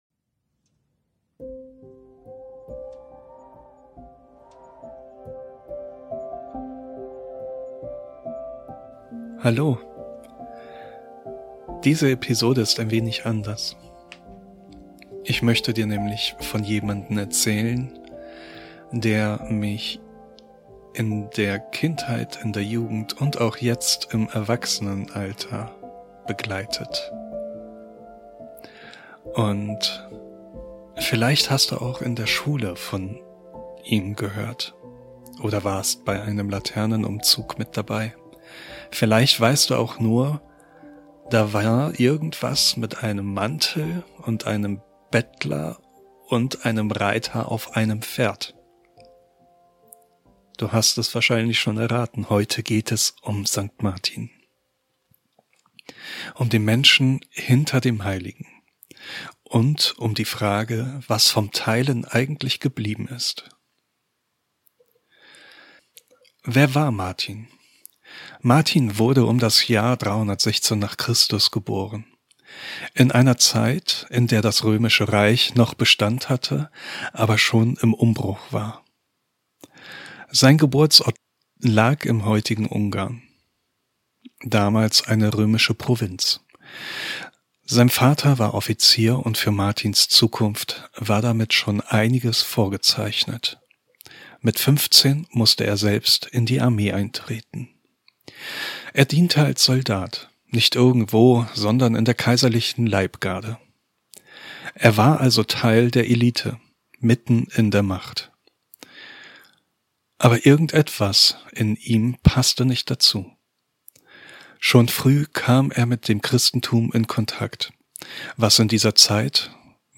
Eine ruhige Erzählung über das Teilen, das Hinschauen und einen